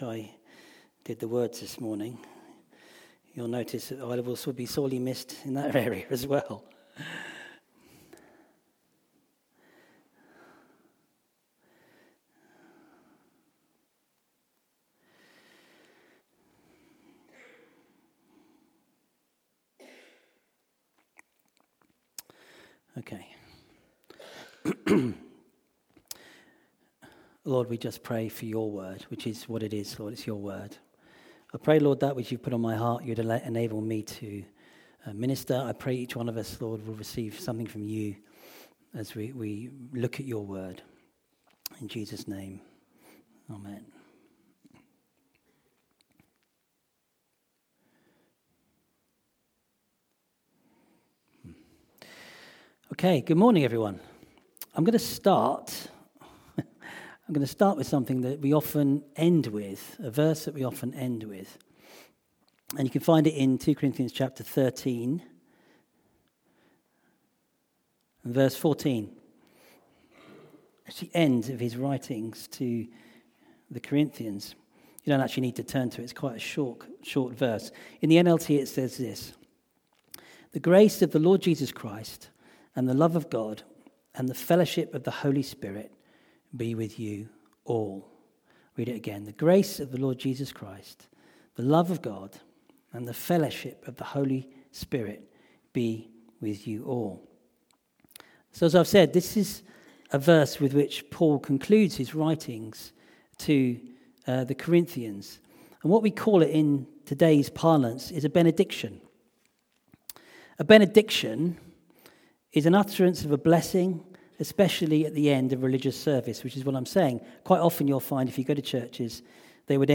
Sermons Archive - Calvary Pentecostal Church